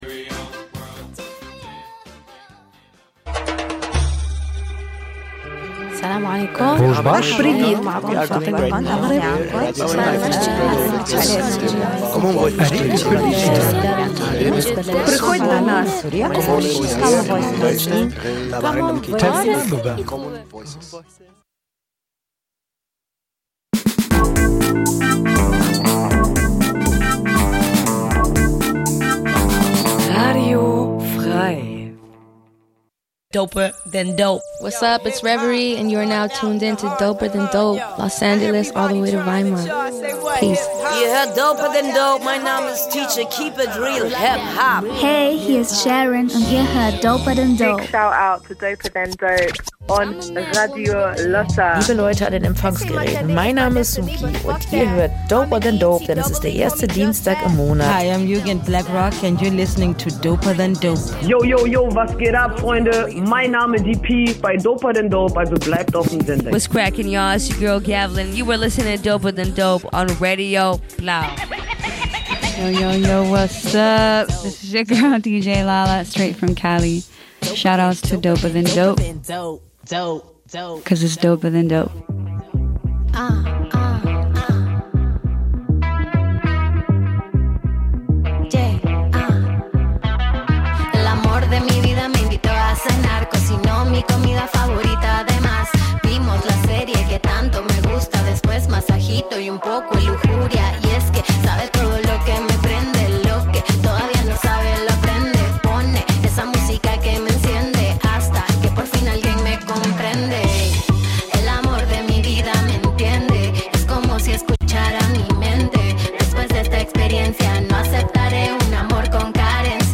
Bei doper than dope wird HipHop-Kultur gefeiert! Dabei werden auch Entwicklungen und wichtige Releases im HipHop Mainstream angeschaut, der Fokus liegt aber vor allem auf Sub-Szenen und Artists, die oft zu wenig Beachtung finden, wie Frauen und queere Rapper*innen.